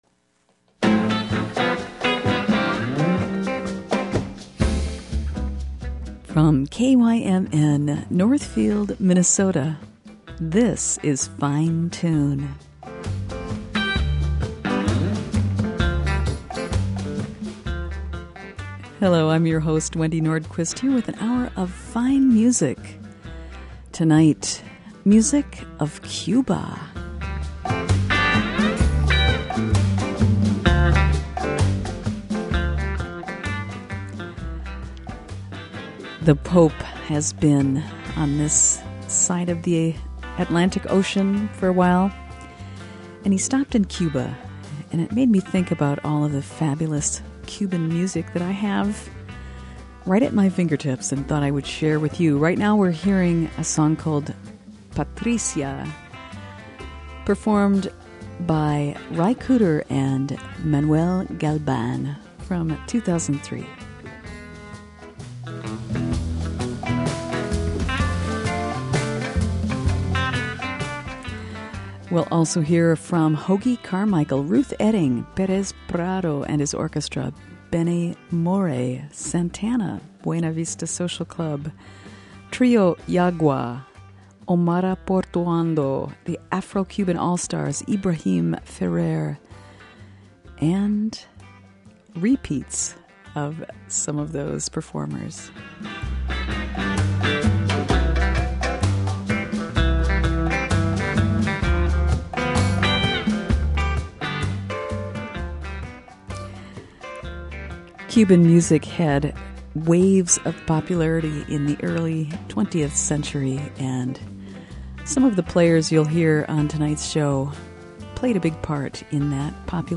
Cuban music